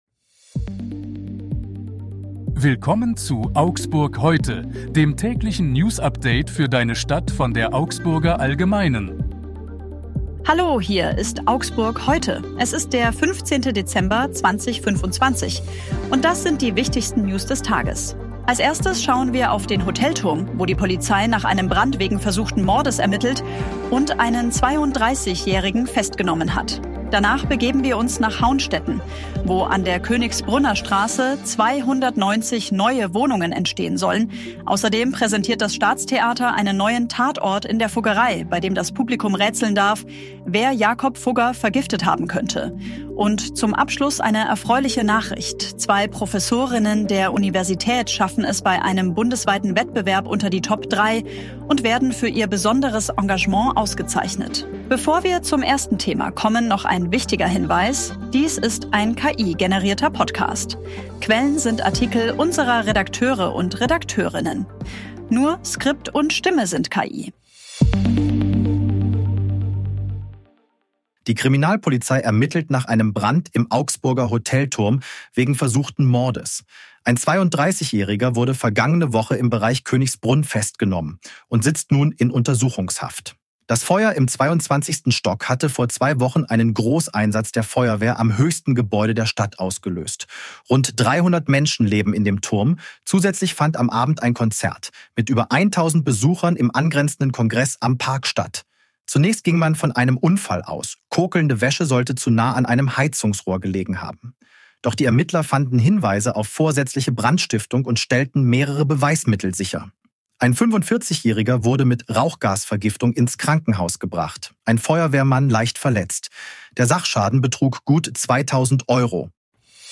Wettbewerb unter die Top drei Dies ist ein KI-generierter Podcast.
Skript und Stimme sind KI.